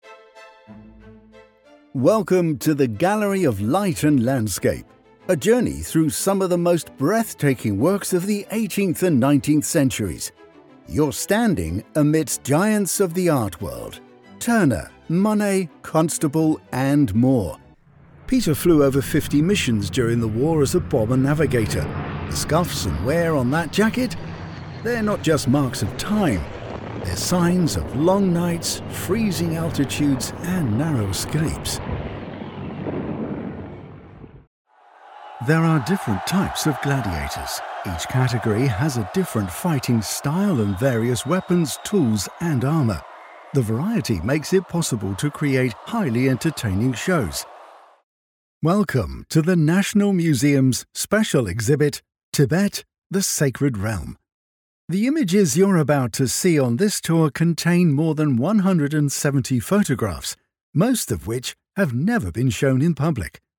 Audio Guides
I have a natural accent free (RP) style voice which is flexible and can adapt to most voiceover projects.
Rode NT1a Condensor Mic, Mac Mini m4, Adobe Audition CC, Scarlett Solo Interface, Tannoy 405 Studio monitors, Sennheiser HD 280 Pro monitoring headphones.
Deep